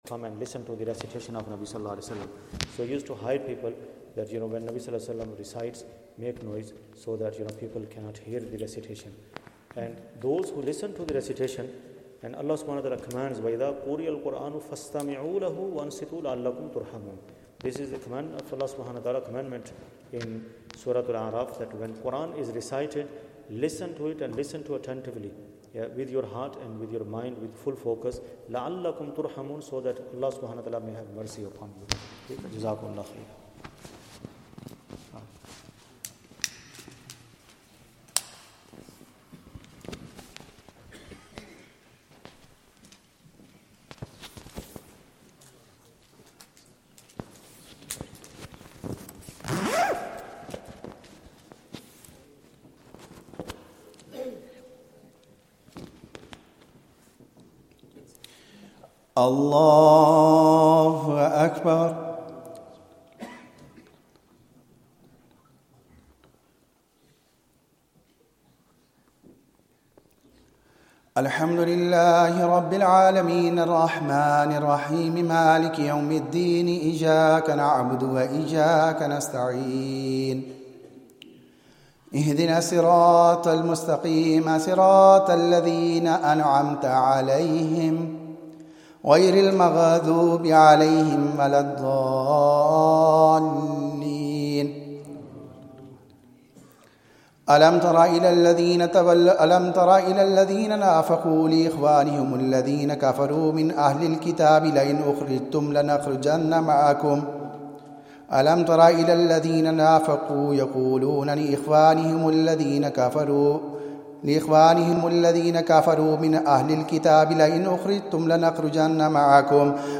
Taraweeh 26th night of Ramadhan, juz 28th part 2